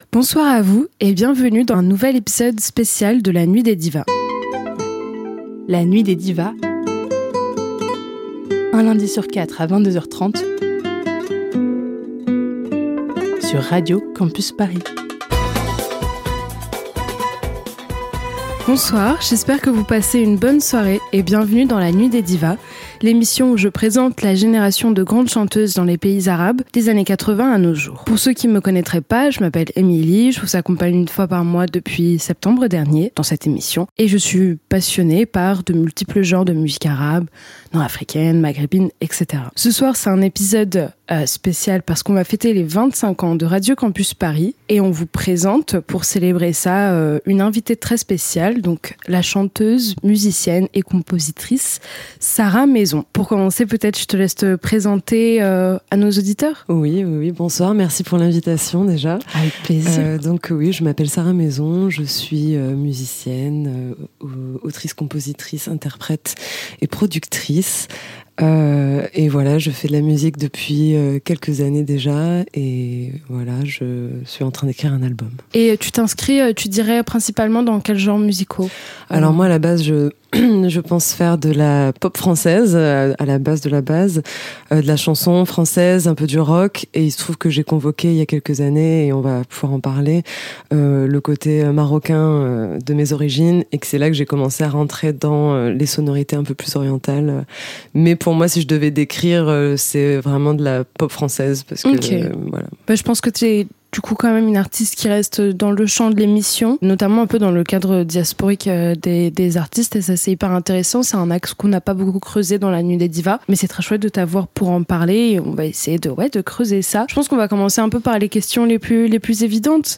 La Nuit des Divas : Interview